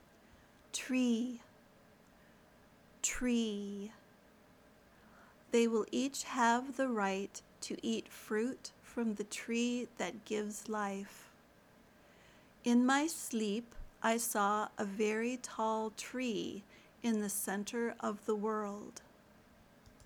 /triː/ (noun)